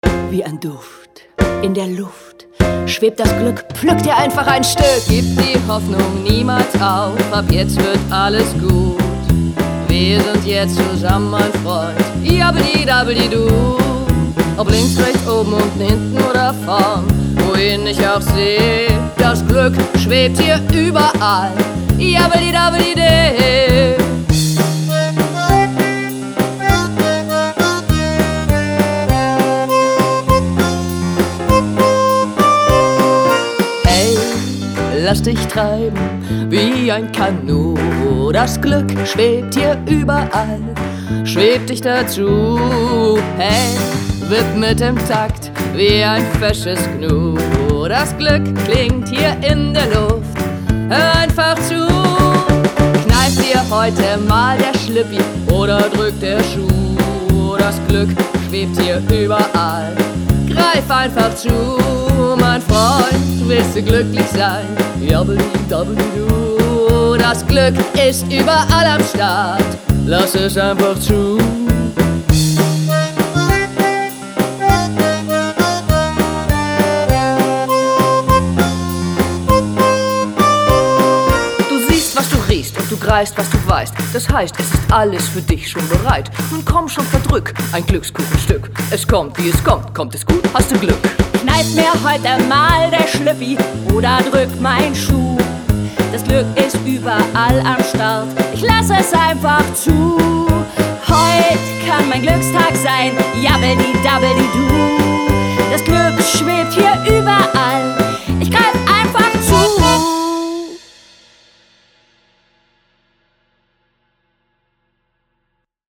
Kategorie: Stückssongs und Sounds